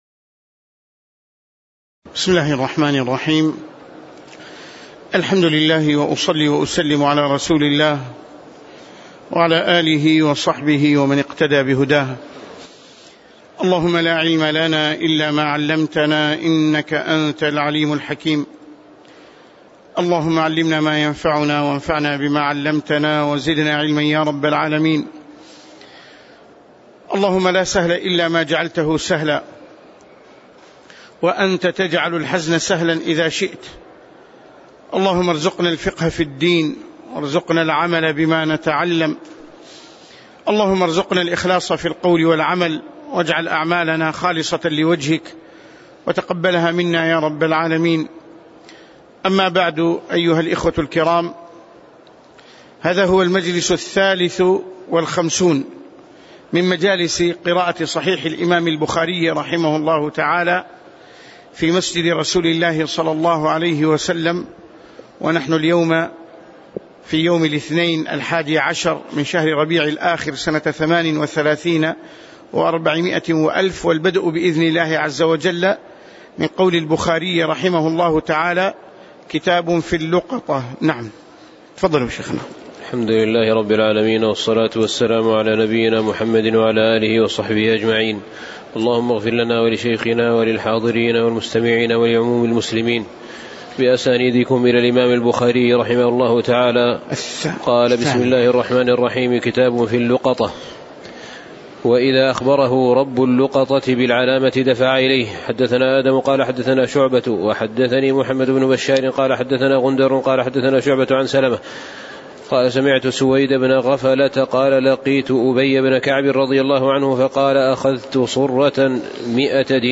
تاريخ النشر ١١ ربيع الثاني ١٤٣٨ هـ المكان: المسجد النبوي الشيخ